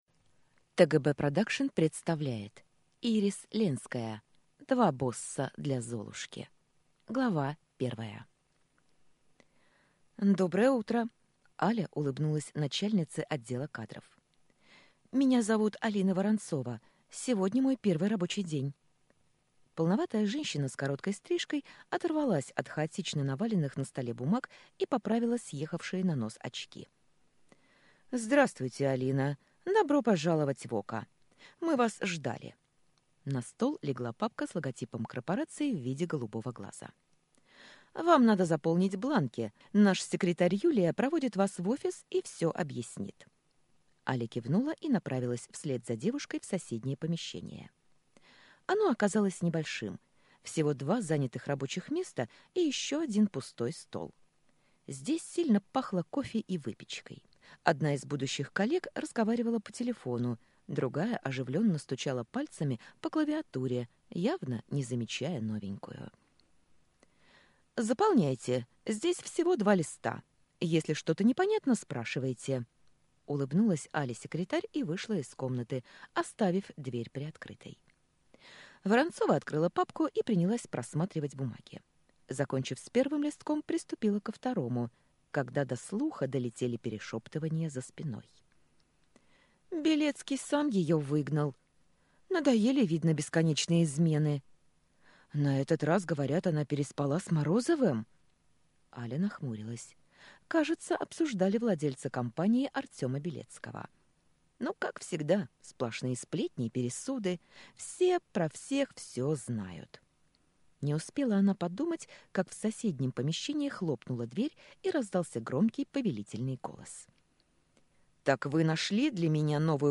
Аудиокнига Два босса для Золушки | Библиотека аудиокниг